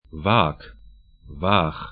Waag va:k Váh va:x sk Fluss / stream 47°55'N, 18°00'E